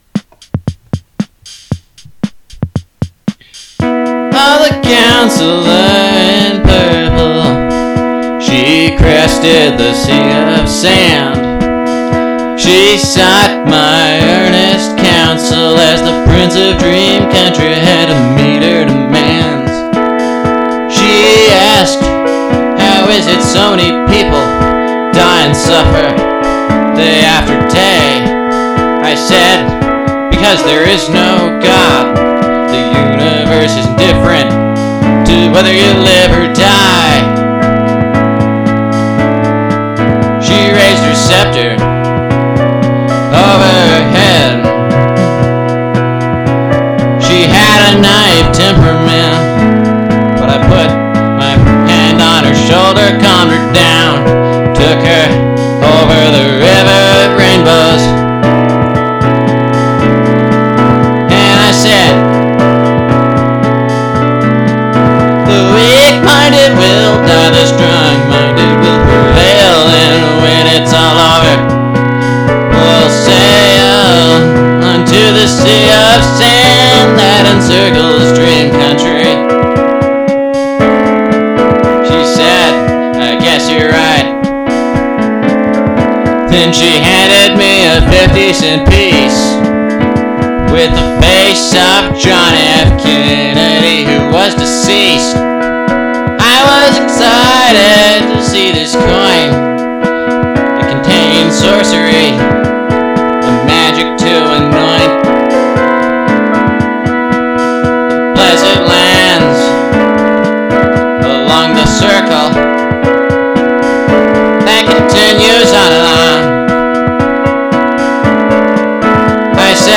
rock, guitar, outsider,